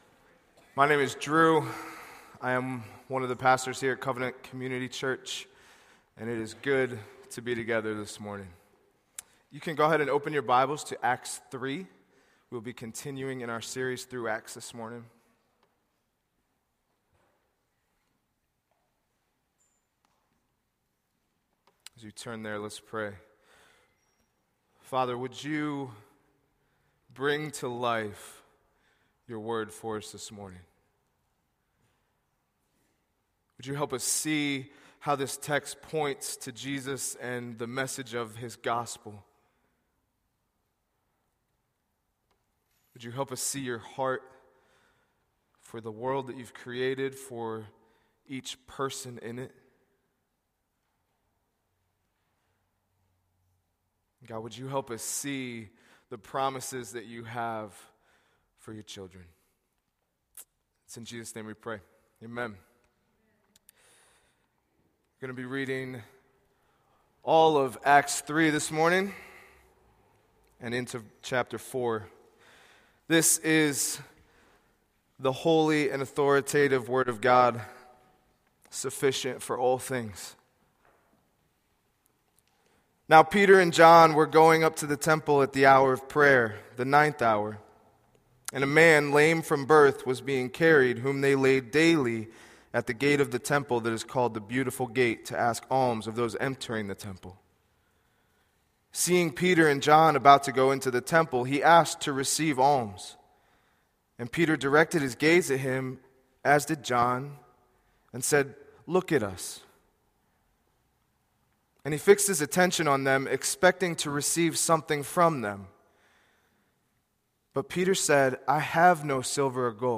A sermon from the series "Stand Alone Sermons."